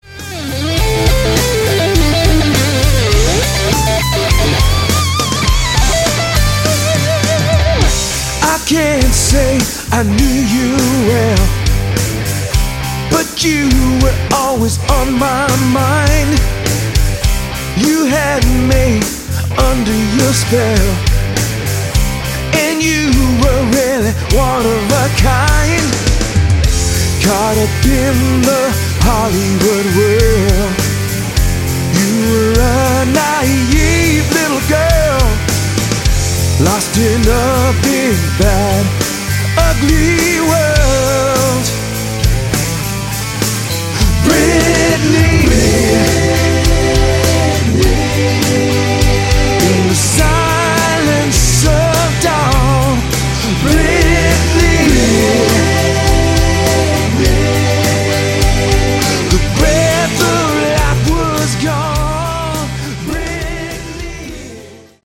lead and backing vocals
guitars
piano
keyboards
saxophone
bass
drums